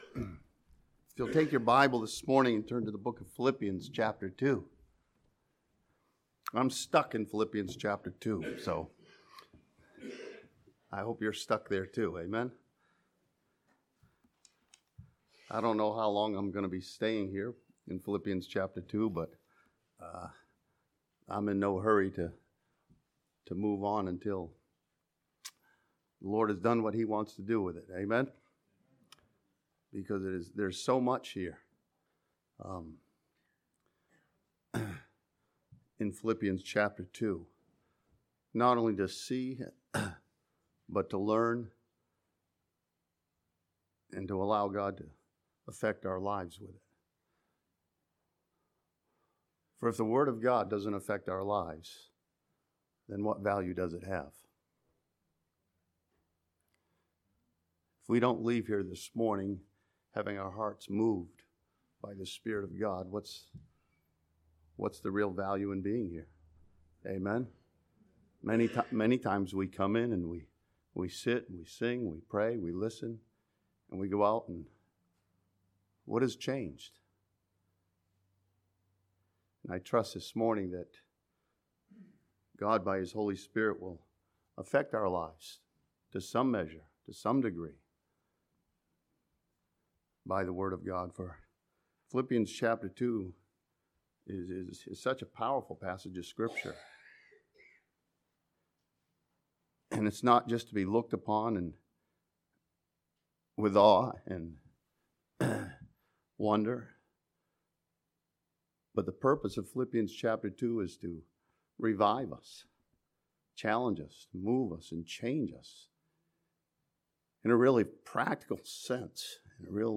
This sermon from Philippians chapter 2 challenges believers to have the mind of Christ to be a light to the world.